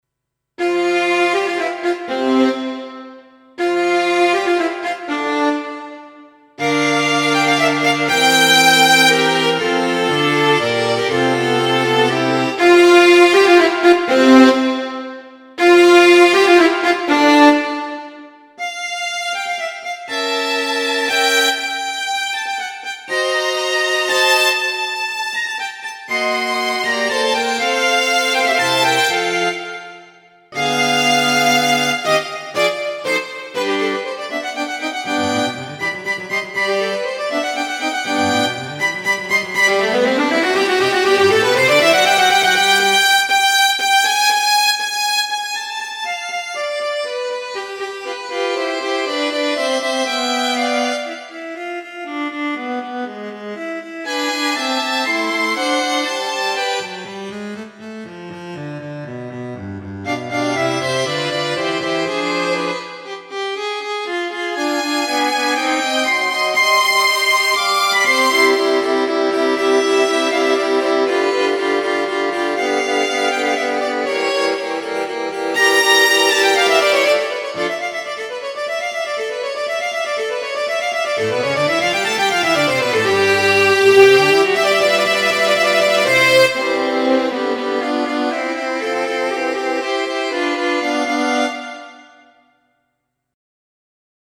pg-demo pa3x violini.mp3